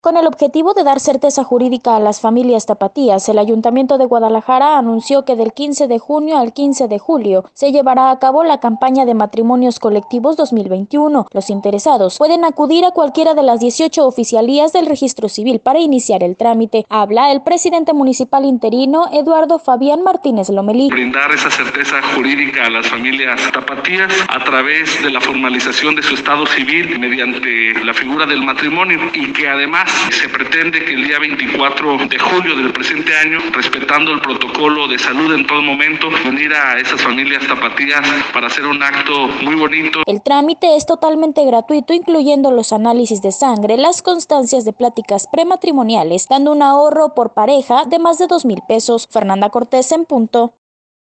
Con el objetivo de dar certeza jurídica a las familias tapatías, el ayuntamiento de Guadalajara, anunció que del 15 de junio al 15 de julio, se llevará a cabo la campaña de Matrimonios Colectivos 2021, los interesados pueden acudir cualquiera de las 18 oficialías del Registro Civil para iniciar el trámite, habla el presidente municipal interino, Eduardo Fabián Martínez Lomelí: